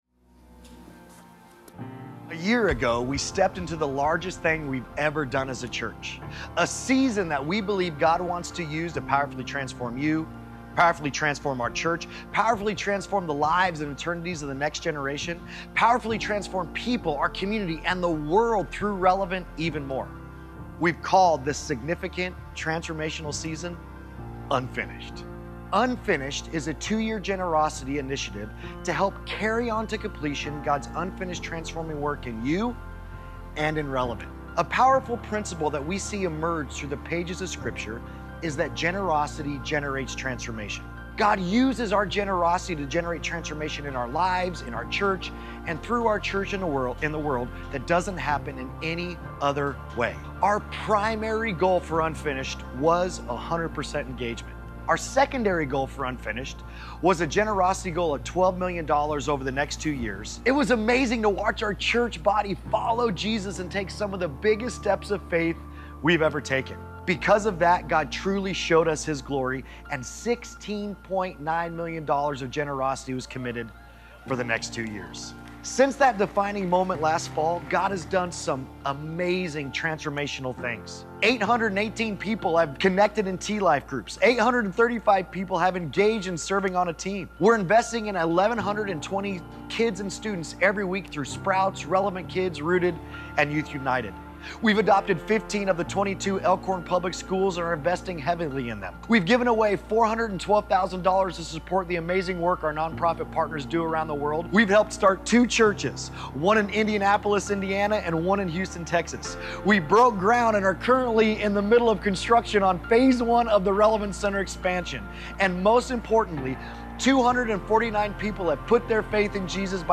Sunday Sermons Immeasurably More, Week 1: "Admit It" Oct 27 2025 | 00:39:14 1x 00:00 / 00:39:14 Subscribe Share Apple Podcasts Spotify Overcast RSS Feed Share Link Embed